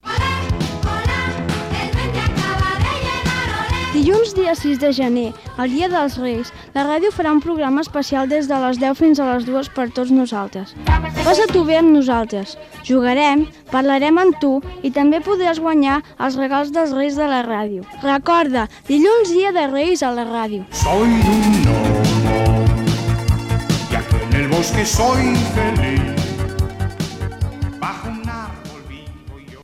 Comercial